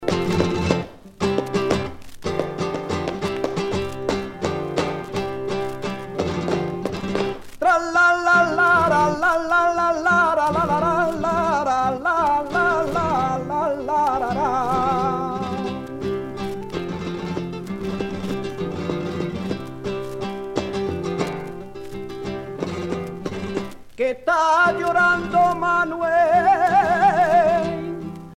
Alegrias